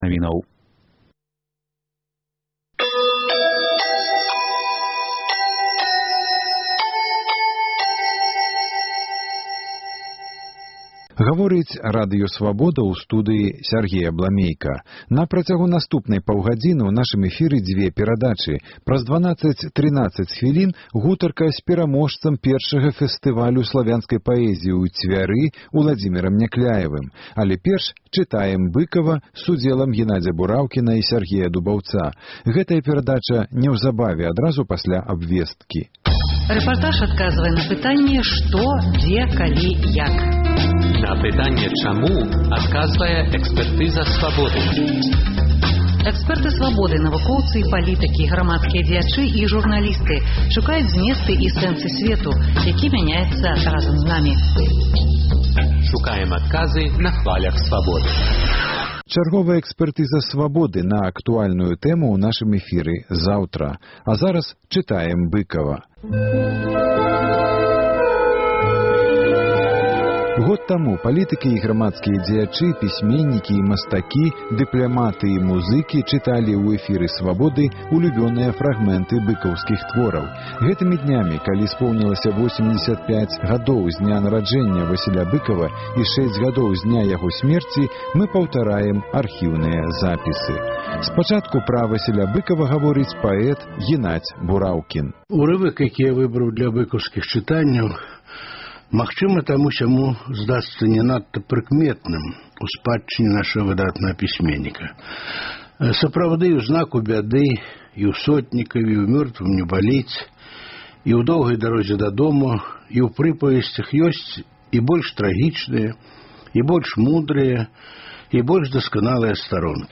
Размова з паэткай Данутай Бічэль пра Васіля Быкава, развагі Аляксандра Фядуты пра кнігу перапіскі Рыгора Барадуліна з маці “Паслаў бы табе душу" і гутарка з Уладзімерам Някляевым і ягоныя новыя вершы ў аўтарскім чытаньні